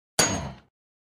Звуки ифрита
На этой странице собраны звуки Ифрита — одного из самых опасных существ Нижнего мира в Minecraft. Здесь вы можете скачать или слушать онлайн его угрожающее рычание, звуки атак, возгорания и другие эффекты.